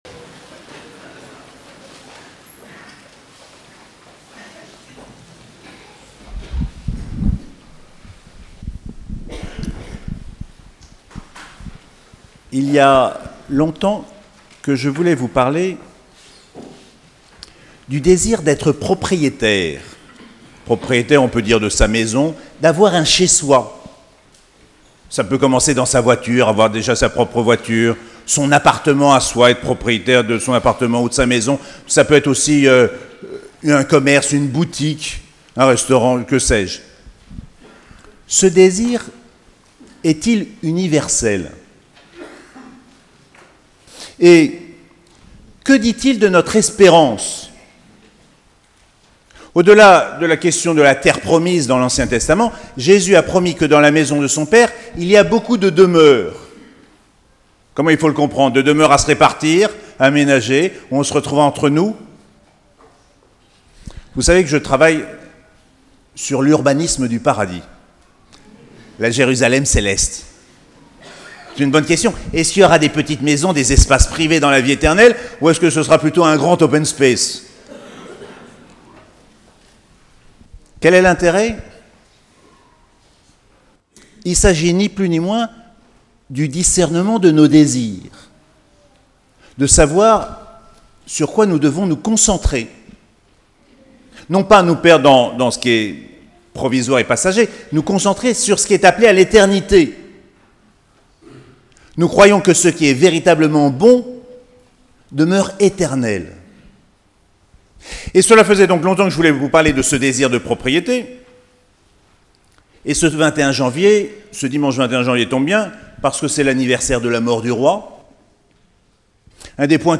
3e dimanche du Temps Ordinaire - 21 janvier 2018